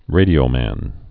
(rādē-ō-măn)